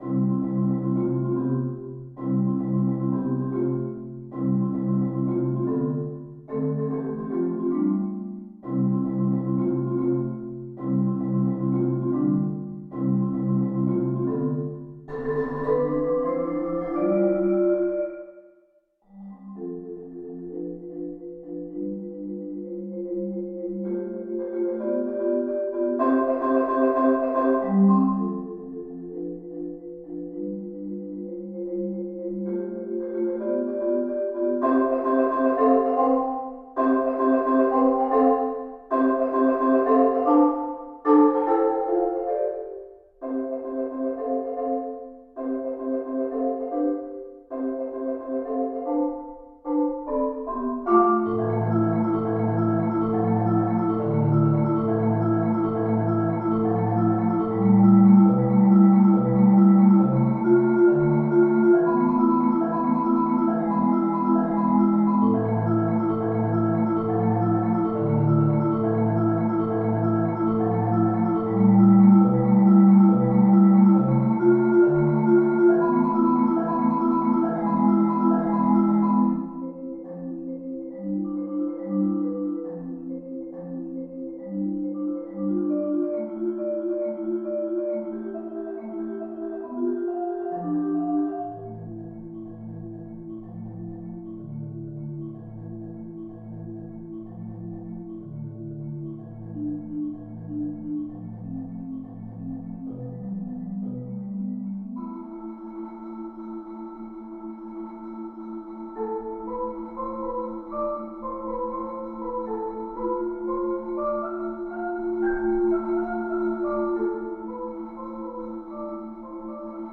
Genre: Marimba (4-mallet)
Marimba (4.3-octave)